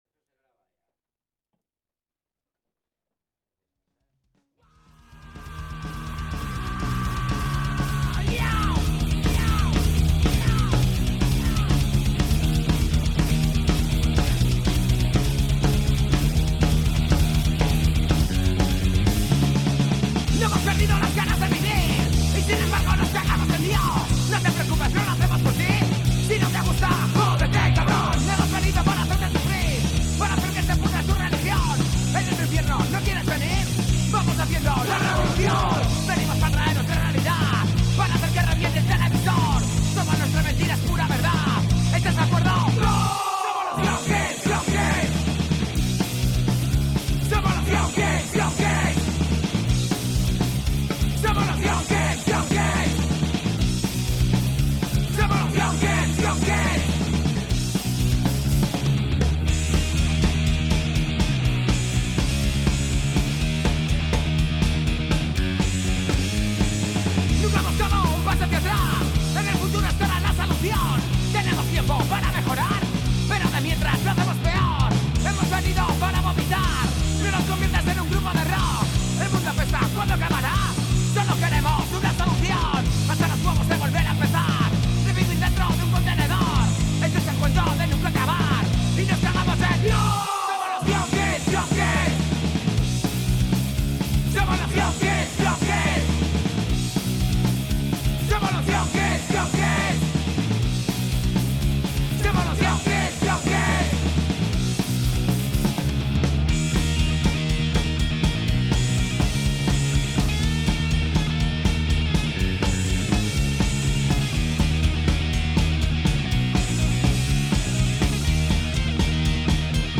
La Maketa: PRG11/T9 Entrevista a YOUNG KIDS
Después tuvimos la ocasión de poder escucharlos en directo y vaya bolako que se cascaron.